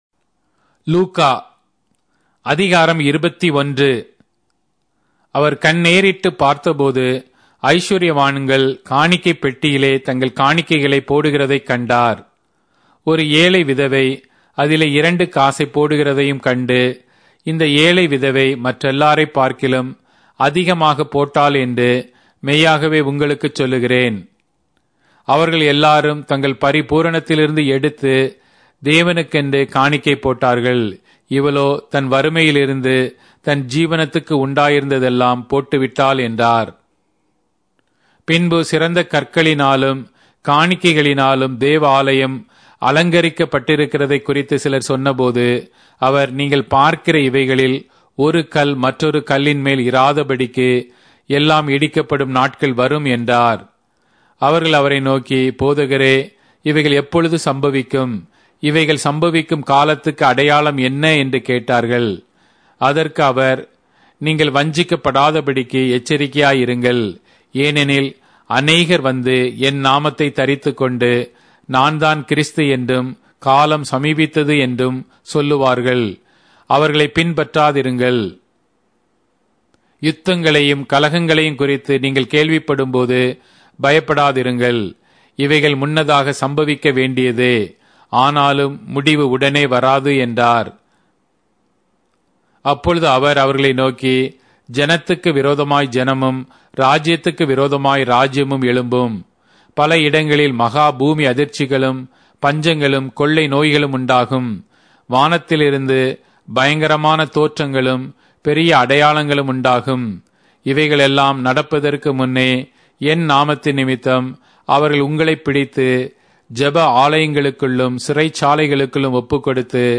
Tamil Audio Bible - Luke 9 in Mrv bible version